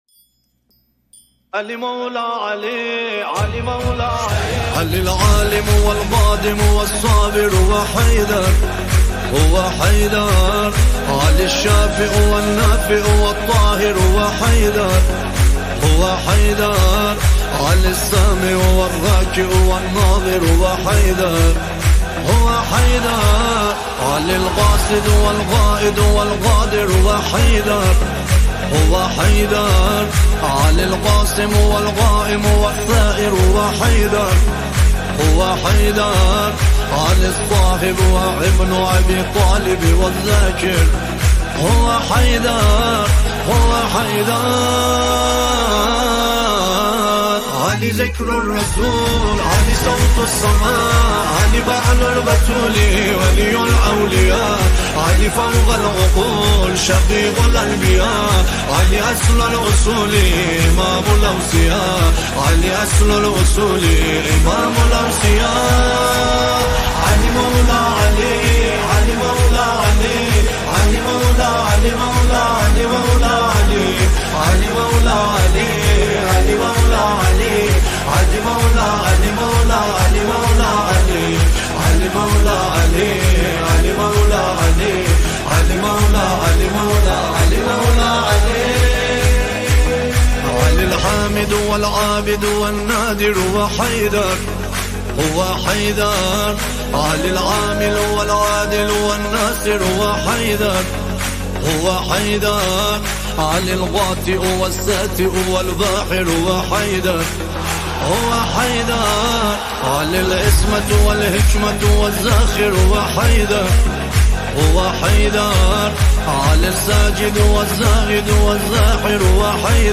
نماهنگ جدید زیبا و دلنشین